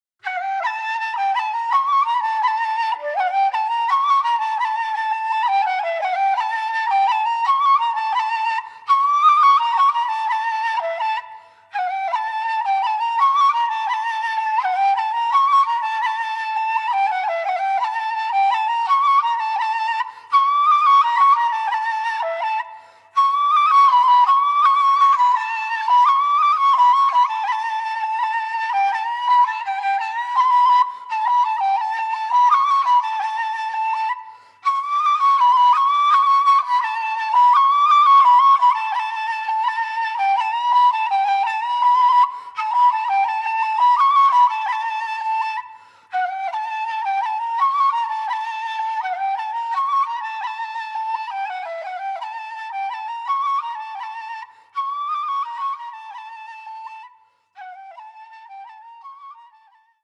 traditional Bulgarian kaval music